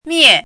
“灭”读音
miè
灭字注音：ㄇㄧㄝˋ
国际音标：miɛ˥˧
miè.mp3